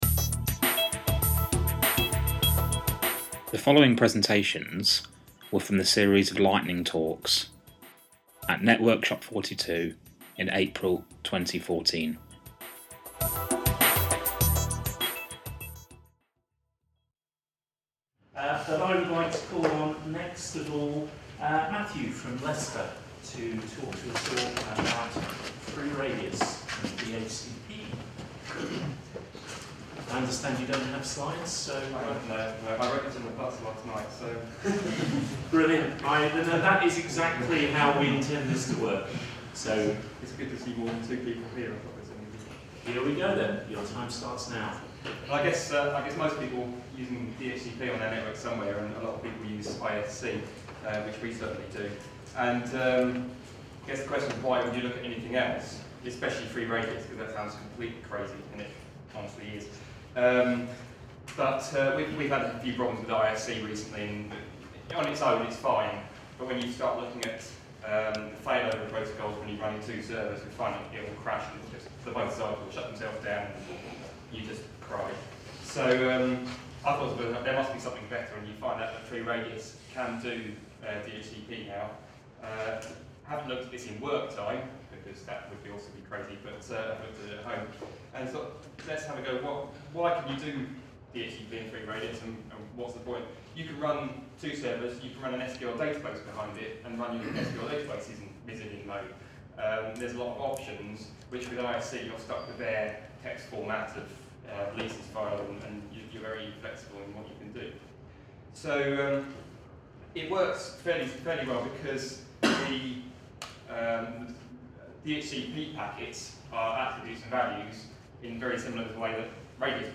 A series of Lightning Talks